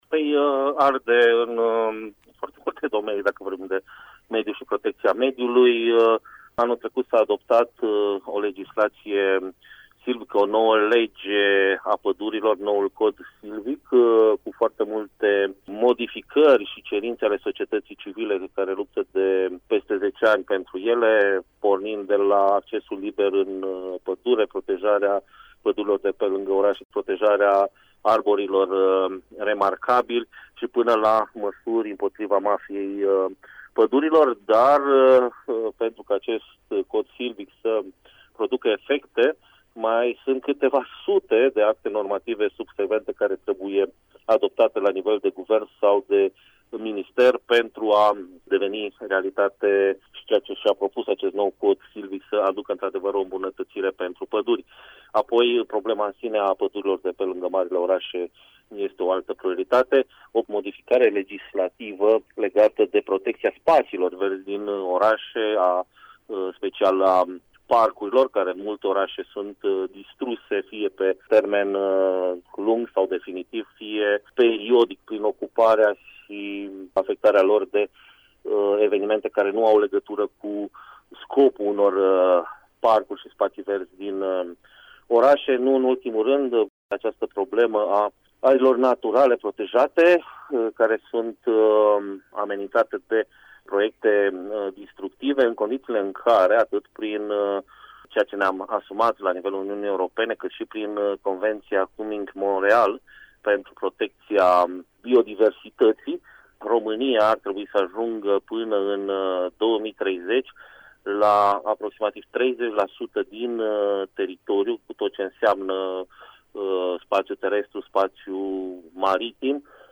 a stat de vorbă cu el: